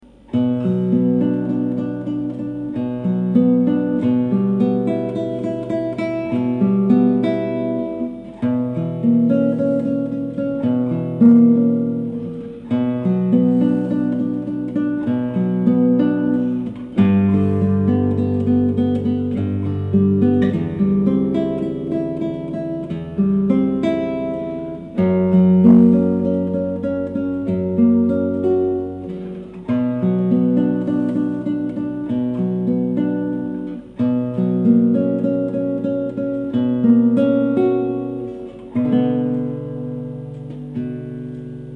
prelude en Si mineur de Carcassi - Guitare Classique
Le son est excellent, l'enregistrement est pur, et j'aime beaucoup ta façon de jouer.